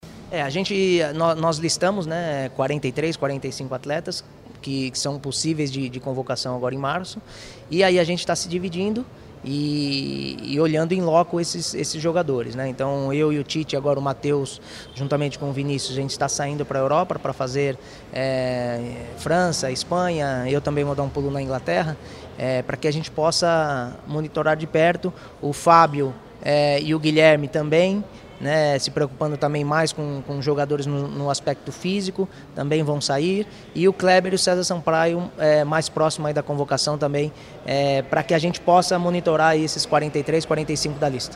Juninho também falou sobre o número de jogadores pré selecionados para essas observações.